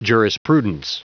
Prononciation du mot jurisprudence en anglais (fichier audio)
Prononciation du mot : jurisprudence